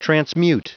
Prononciation du mot transmute en anglais (fichier audio)
Prononciation du mot : transmute
transmute.wav